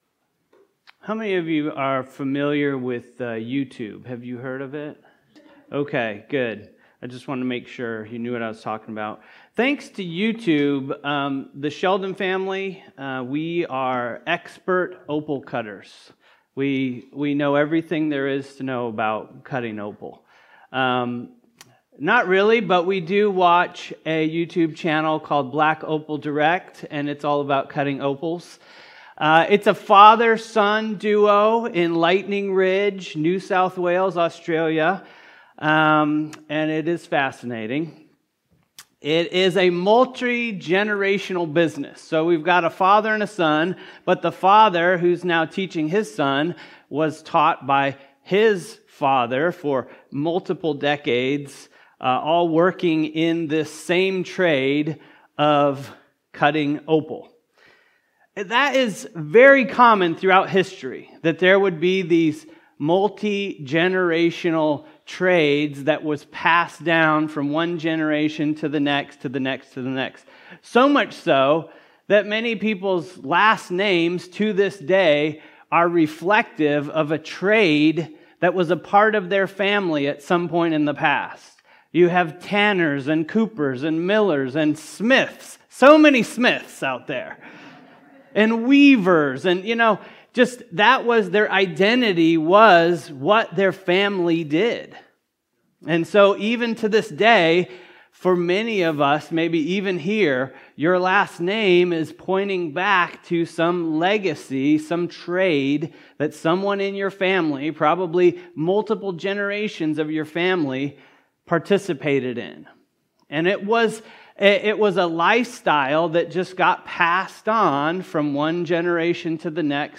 Why Every Christian Must Be a Disciple of Jesus | Ephesians 4 Sermon